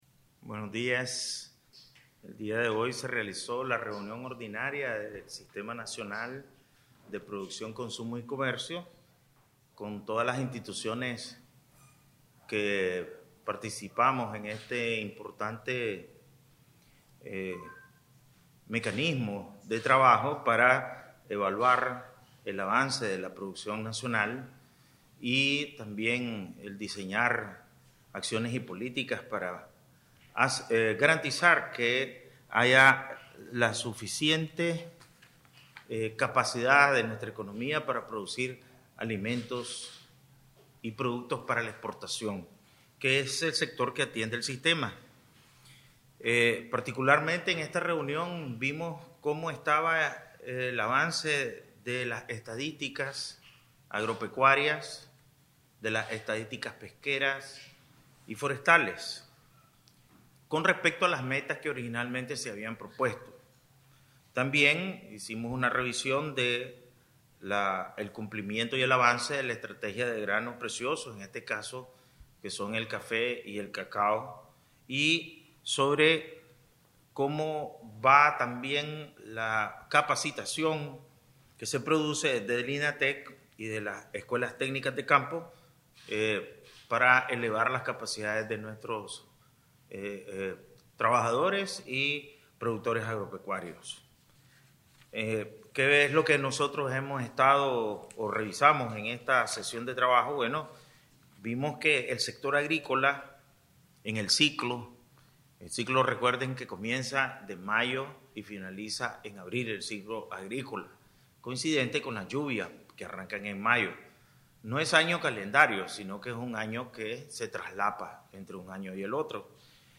El Presidente del Banco Central de Nicaragua (BCN), Ovidio Reyes R., expuso en una conferencia de prensa, efectuada el 17 de marzo de 2023, posterior a la reunión ordinaria del Sistema Nacional de Producción, Consumo y Comercio, realizada en esa misma fecha, los principales avances en materia de producción para el ciclo agrícola 2022-2023, así como las acciones y políticas orientadas a garantizar la suficiente capacidad de la economía para producir alimentos y productos para la exportación.